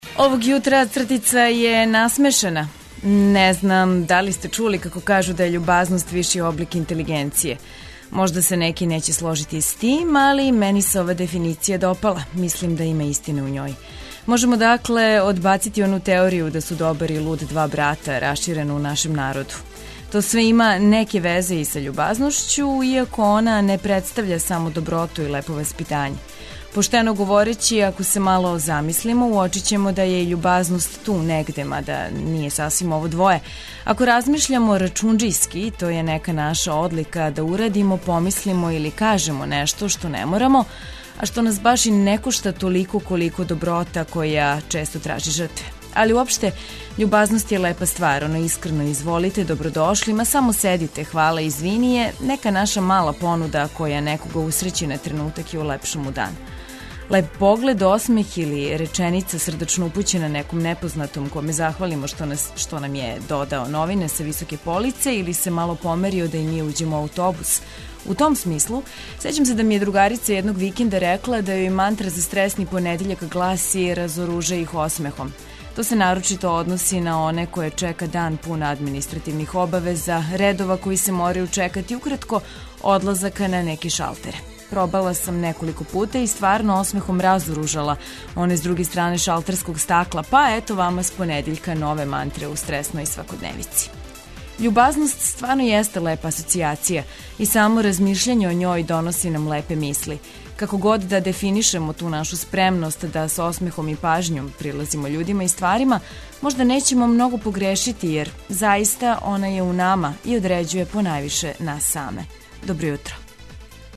Уз актуелности и полетну музику желимо вам добро јутро!